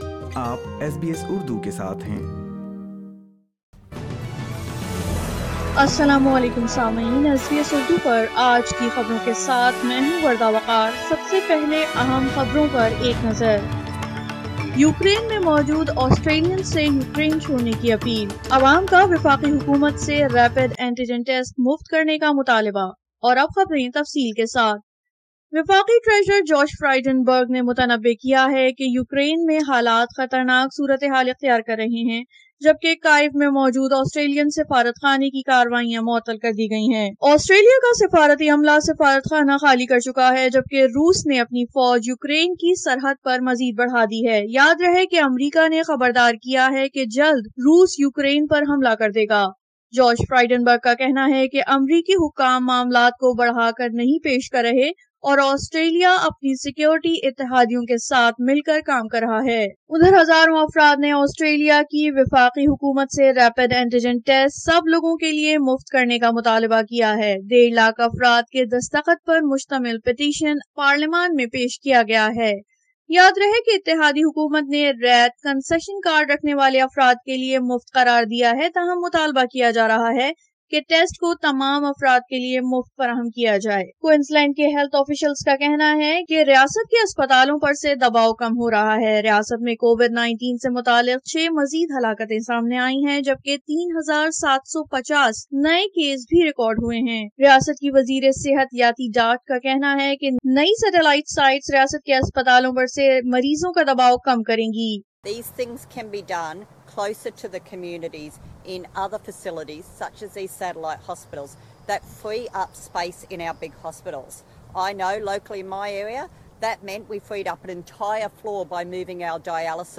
SBS Urdu News 14 February 2022